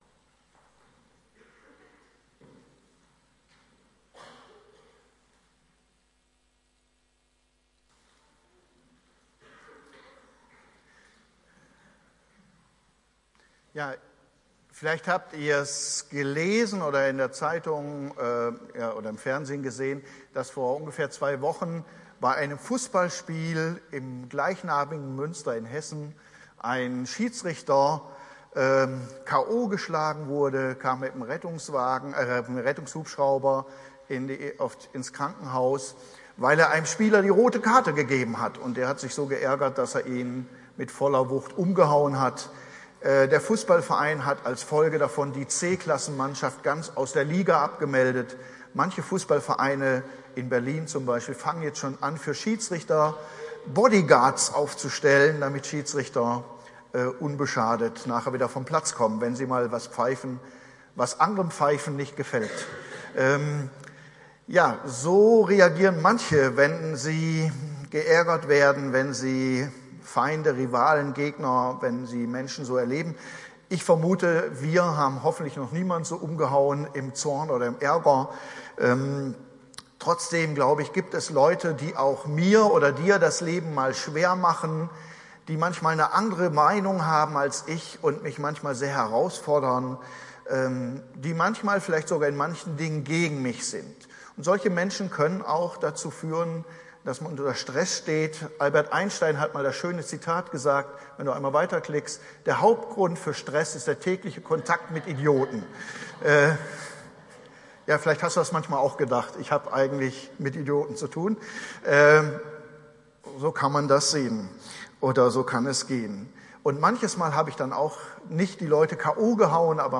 Predigtreihe: Die Bergpredigt